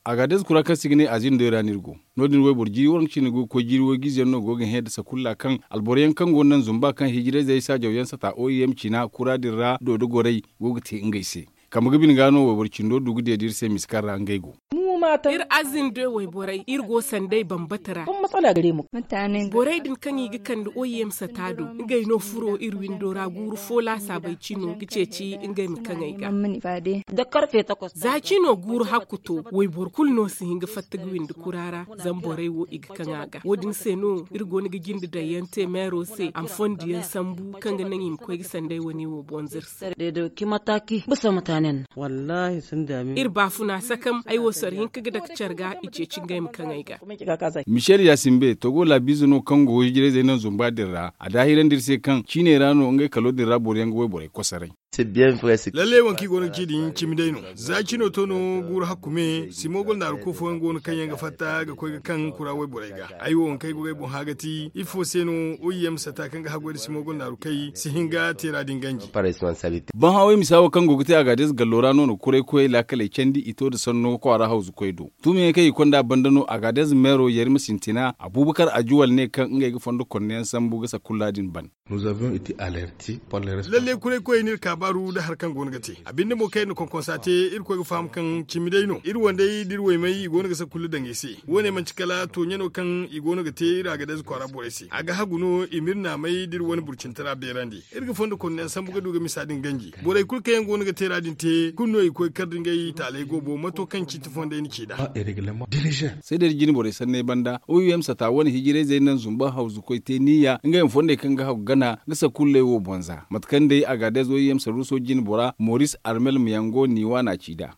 Le reportage